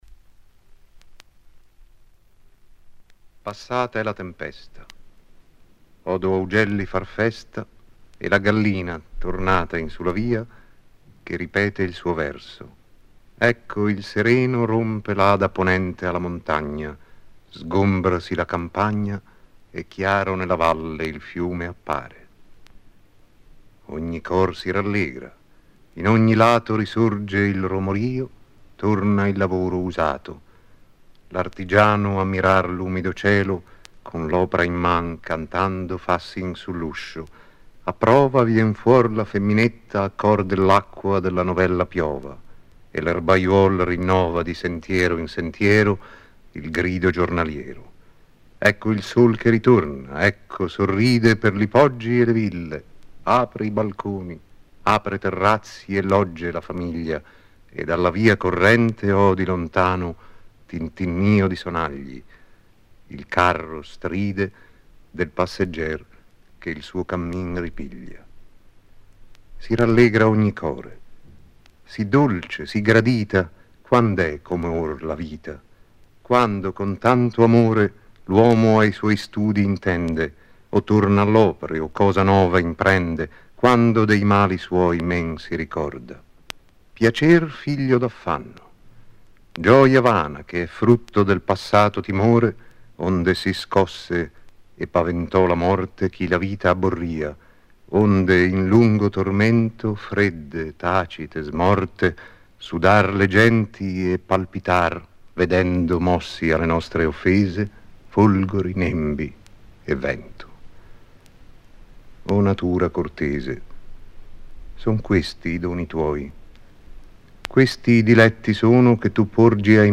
Vittorio Gassman recita: